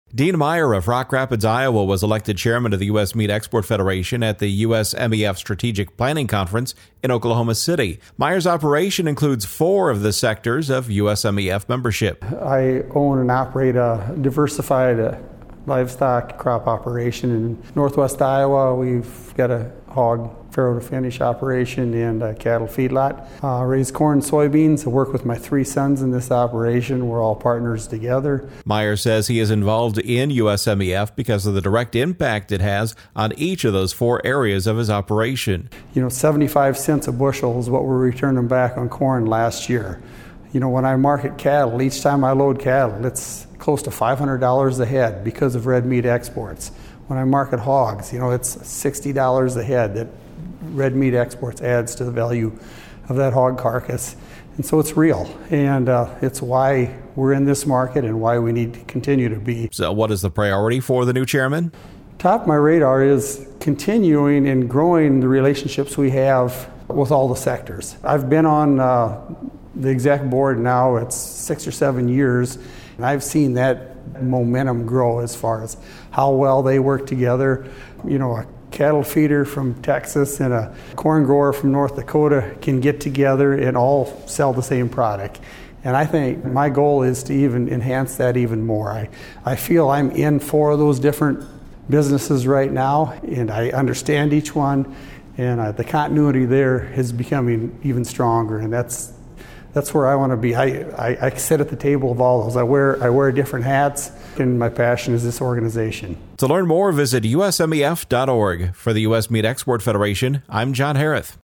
In the attached audio report